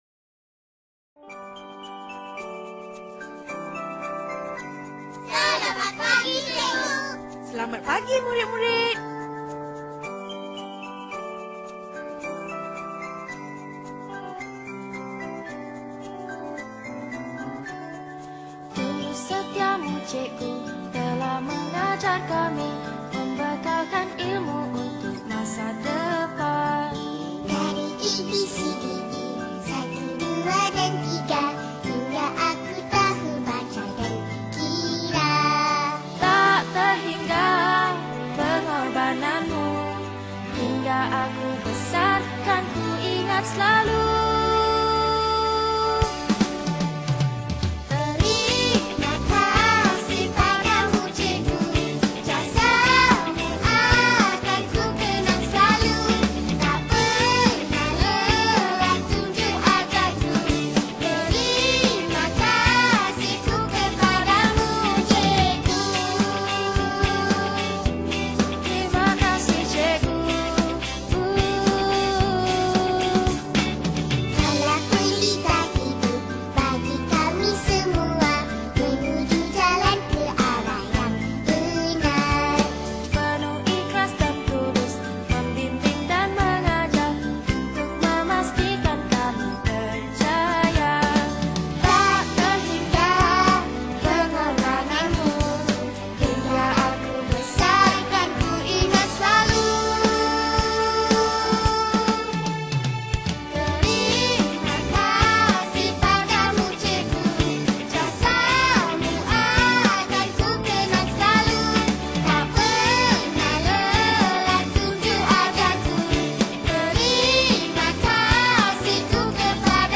Angklung Volume 7
Malay Song